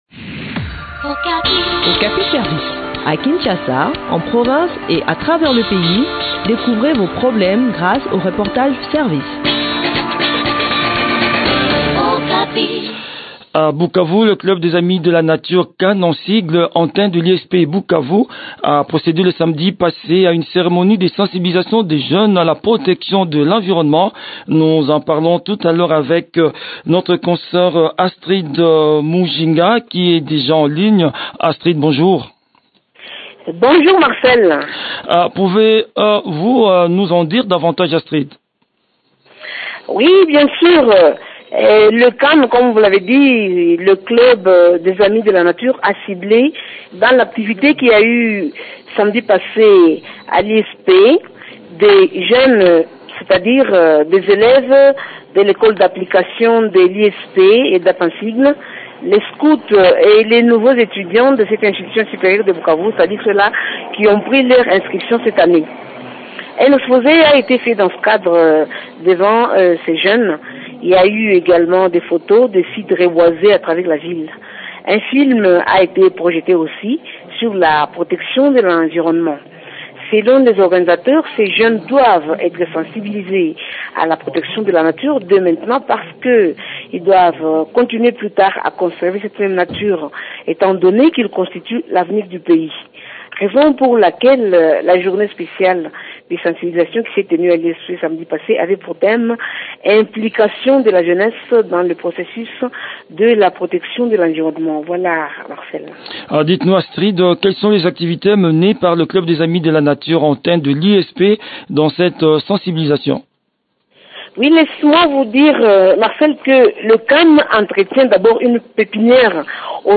Le point sur le déroulement de cette campagne de sensibilisation dans cet entretien